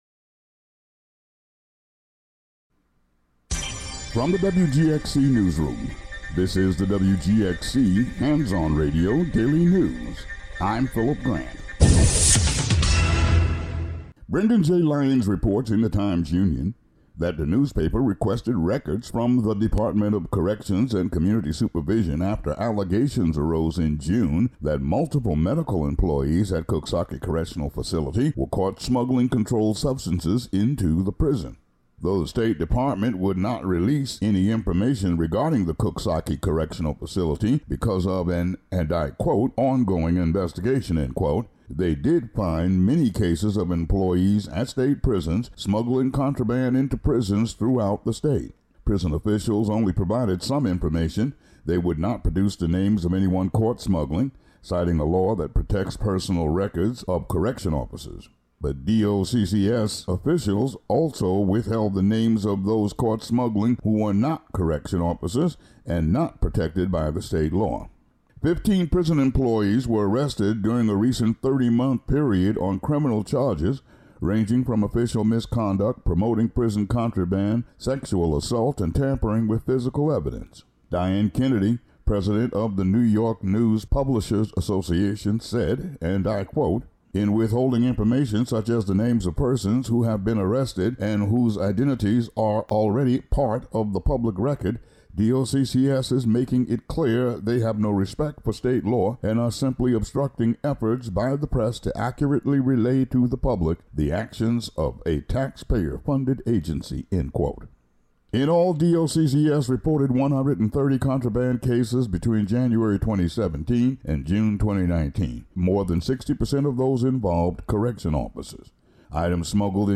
Click on "Audio" above to hear today's local news.